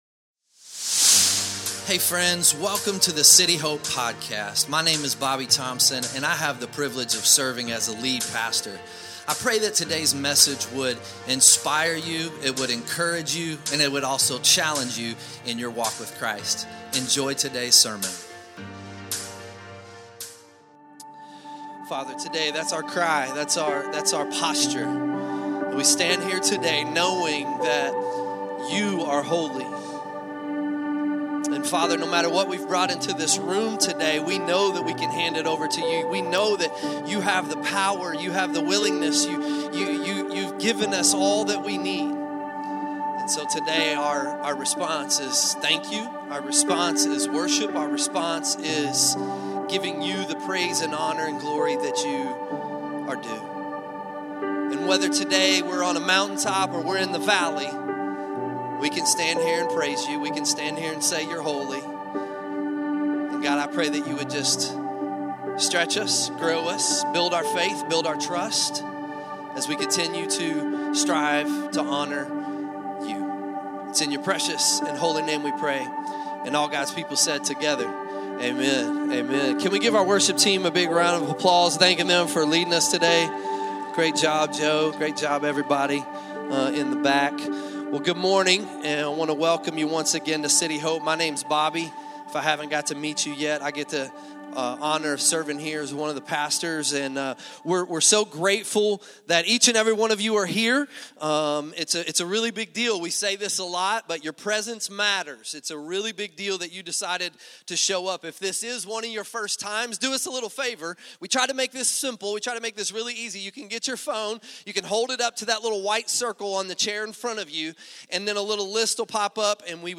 2026 Sunday Morning This six-week series walks through the book